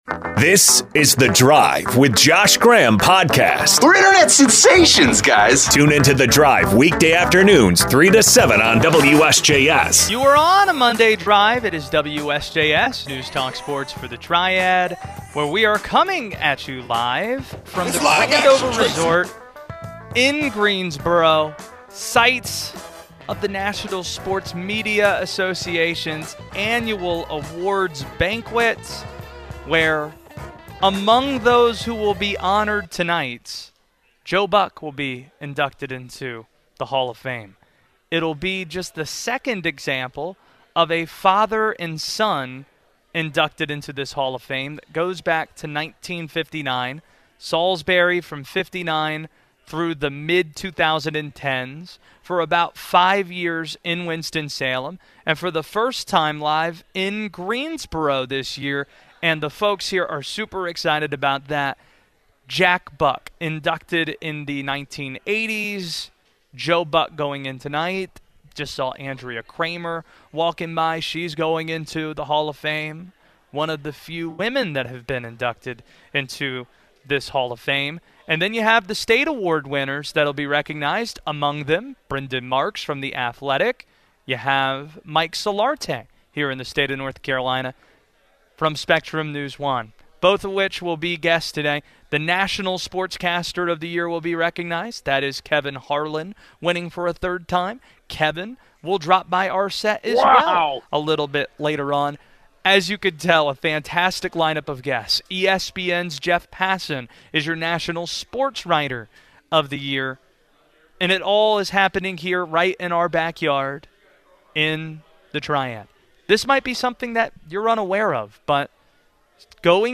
live from the NSMA Awards at the Grandover Resort in Greensboro